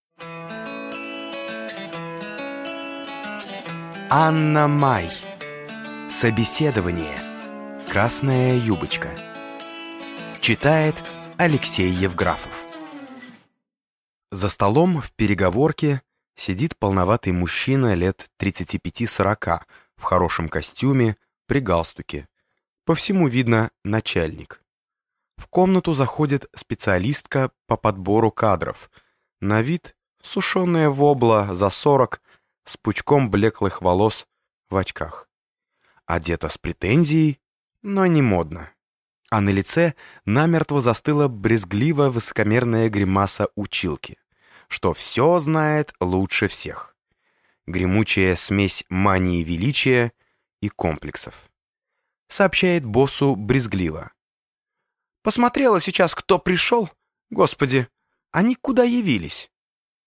Аудиокнига Собеседование (Красная юбочка) | Библиотека аудиокниг